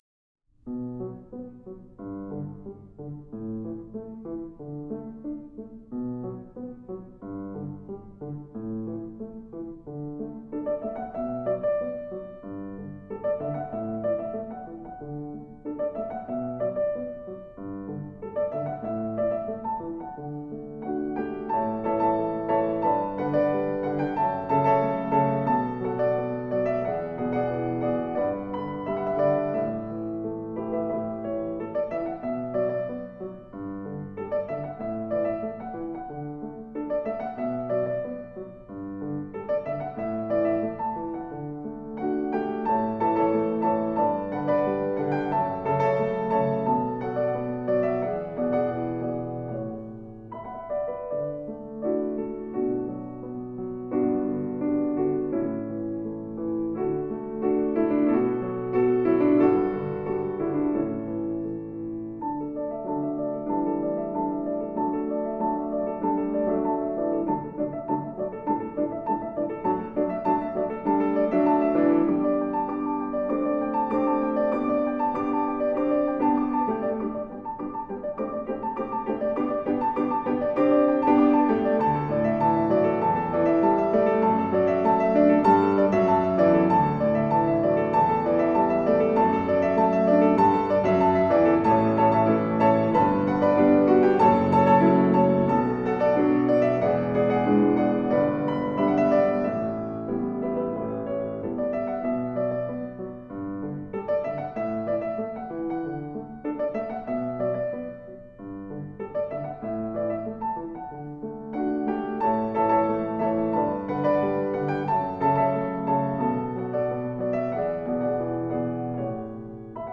专辑内的曲目来自影视作品的配乐